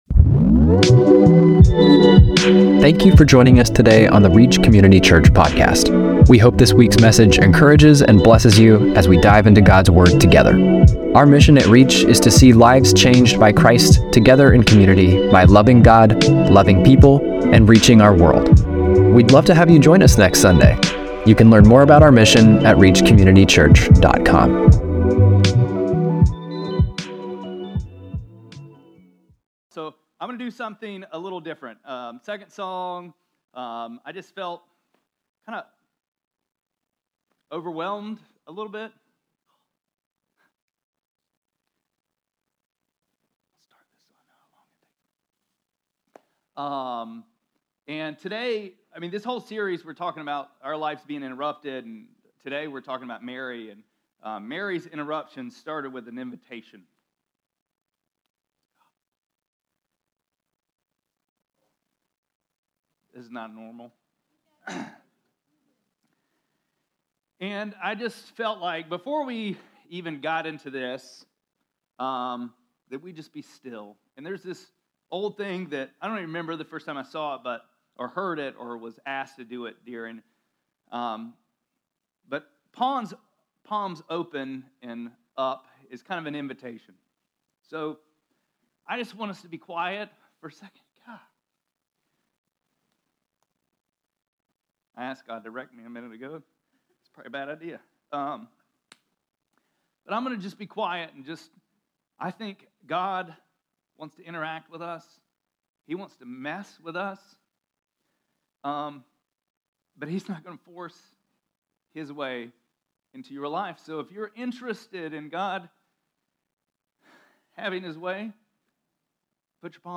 12-8-24-Sermon.mp3